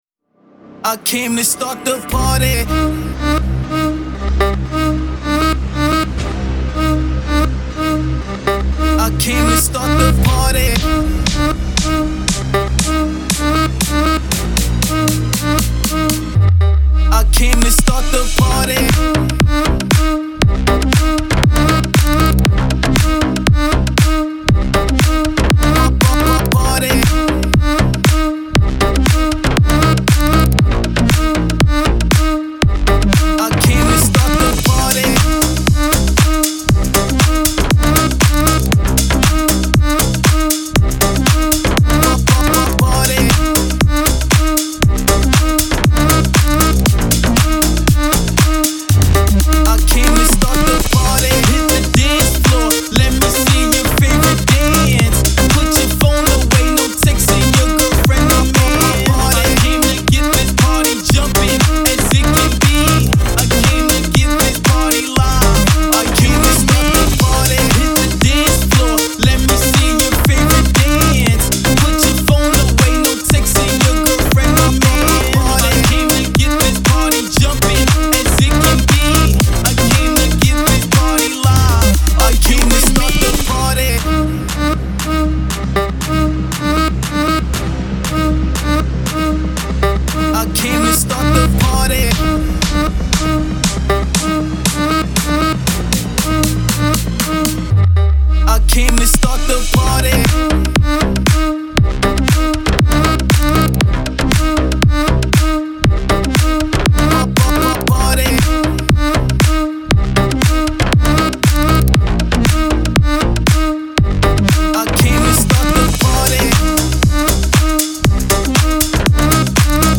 Клубная